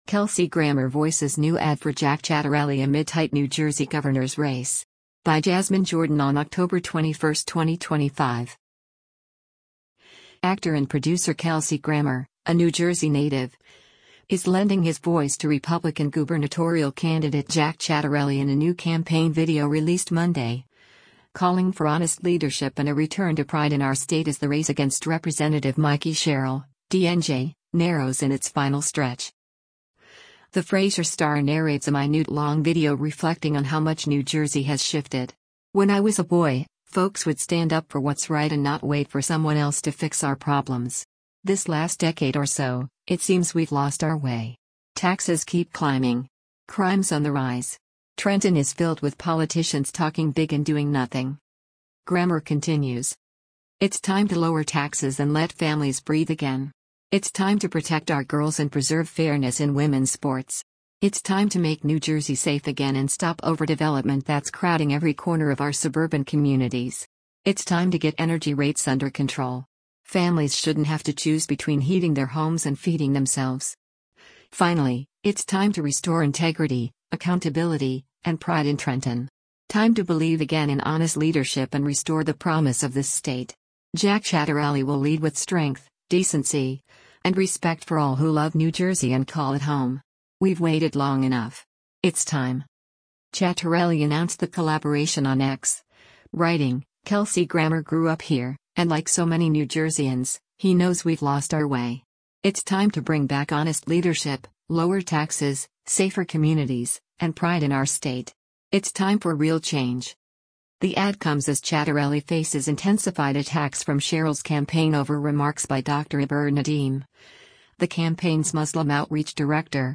Kelsey Grammer Voices New Ad for Ciattarelli in Tight N.J. Race
The Frasier star narrates a minute-long video reflecting on how much New Jersey has shifted.